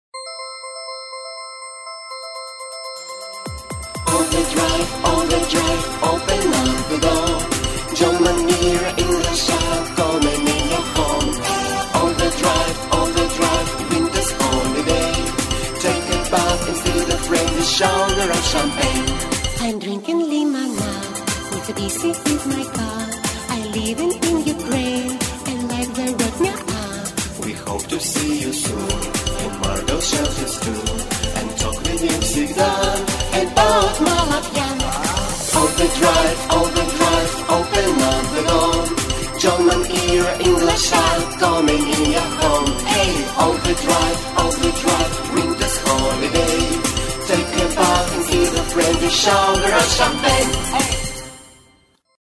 Музыка - народная
Хор:
Поэтому так много звона.